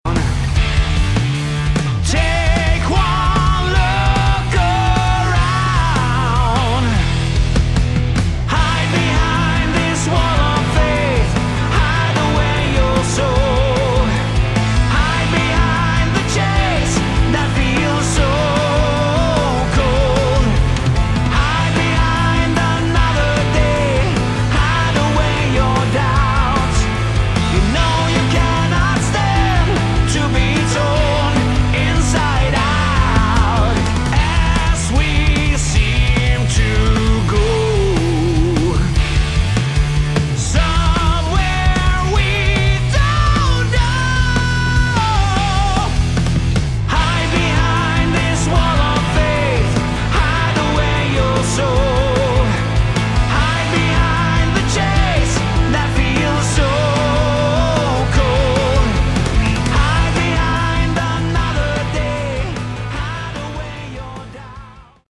Category: Hard Rock
guitar
vocals
bass
organ, keyboards
drums